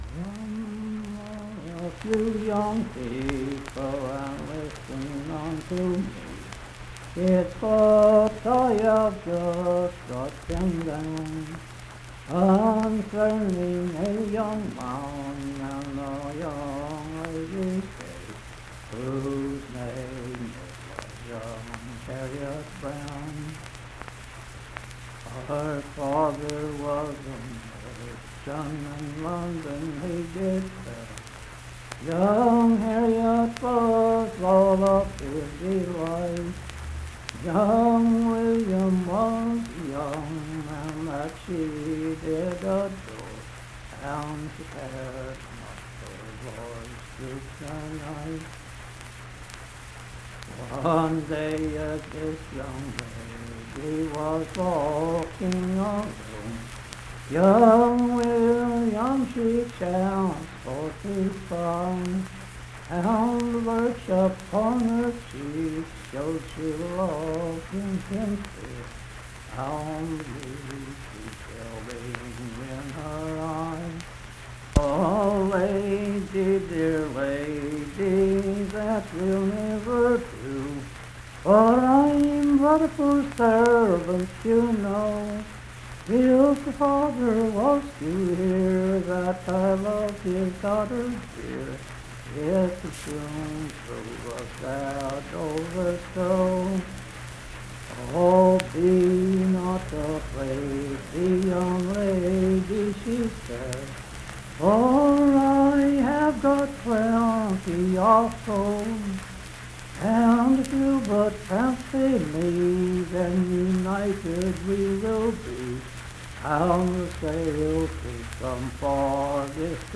Unaccompanied vocal music
Voice (sung)
Mannington (W. Va.) , Marion County (W. Va.)